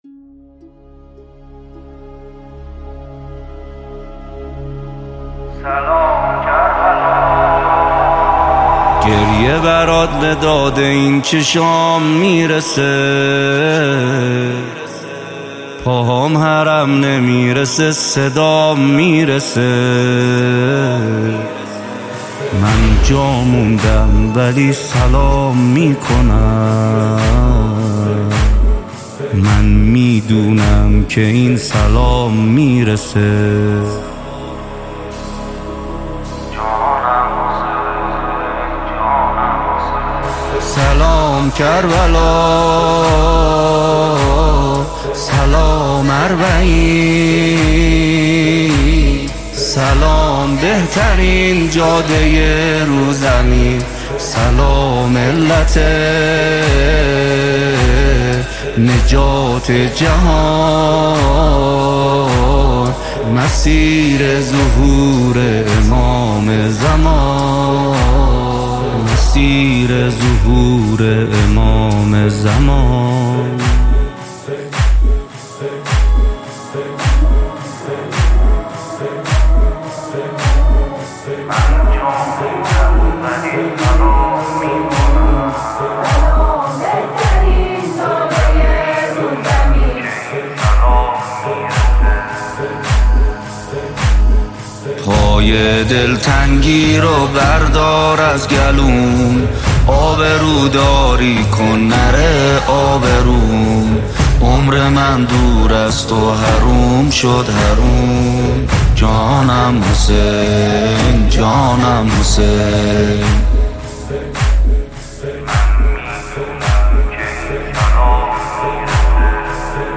مذهبی